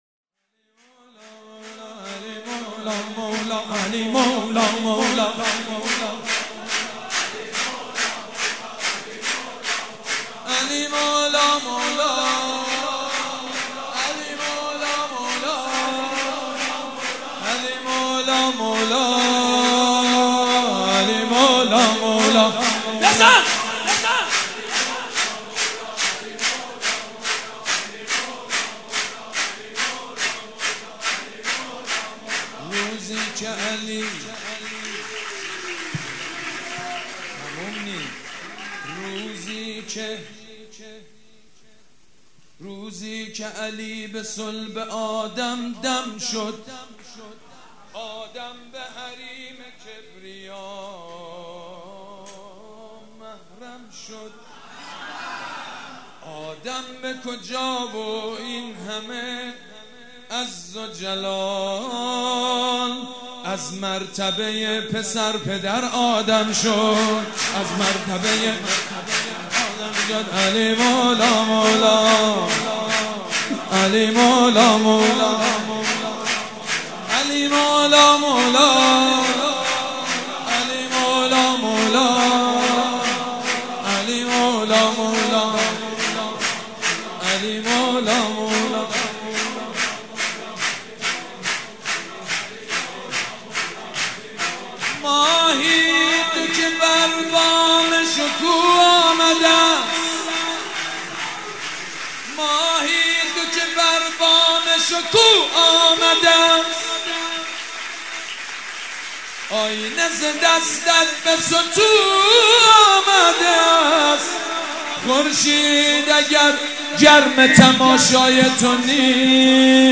۱۷ ربیع الاول ١٤٣٨ | جمعه ۲٦ آذر ماه ۱۳۹۵ هيئت ريحانة الحسين(س)
سبک اثــر شور مداح حاج سید مجید بنی فاطمه